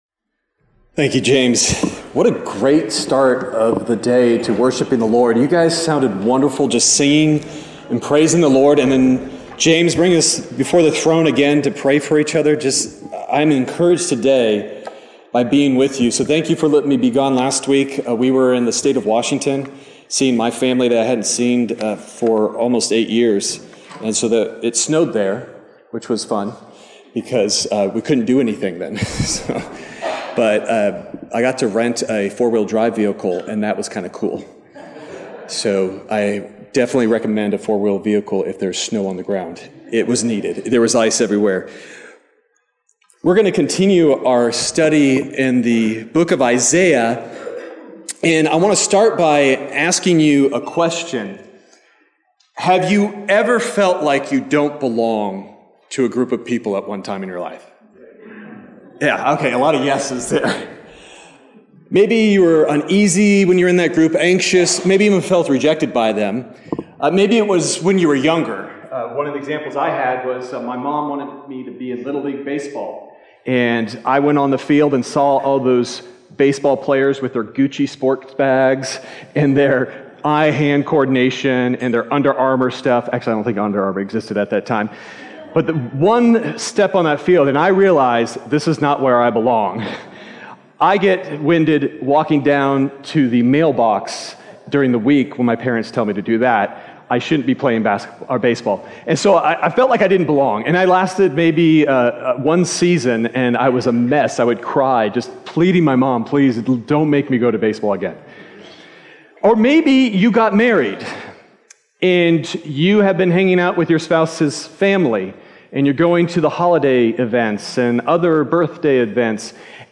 Sermon Detail
January_21st_Sermon_Audio.mp3